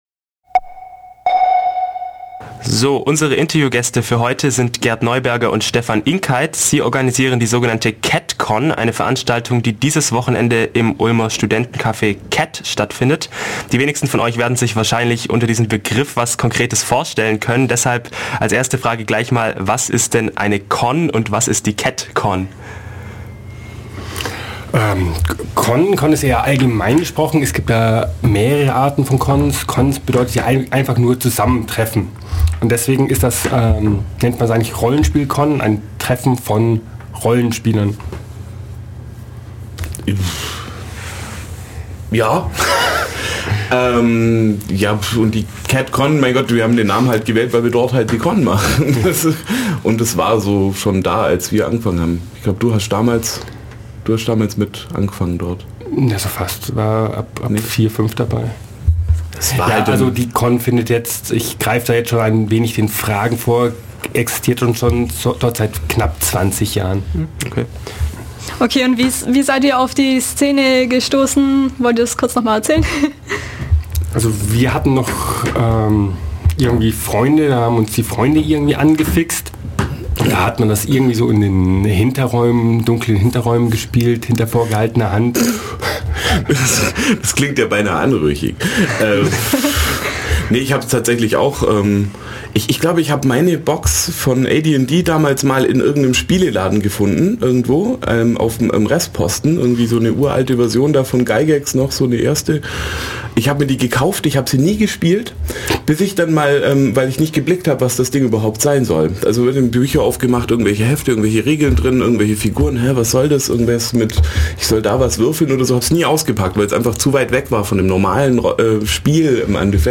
Genre Radio
interview_catcon.mp3